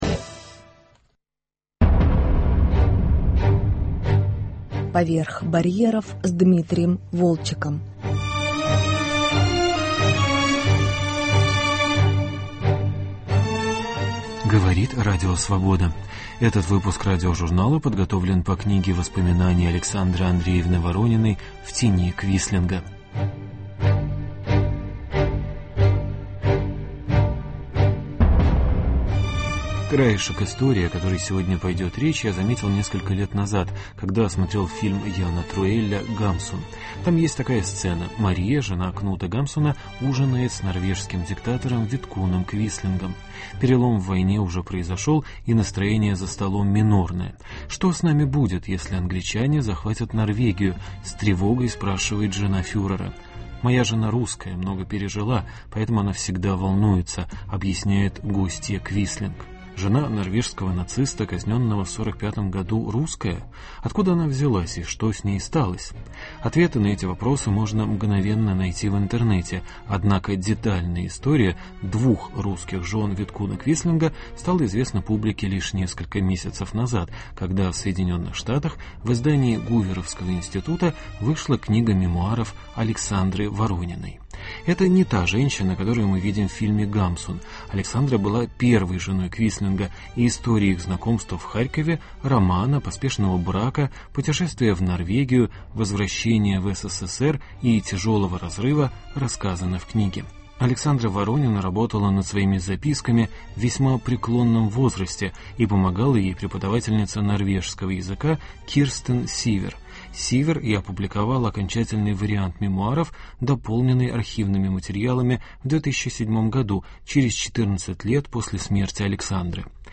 буги-вуги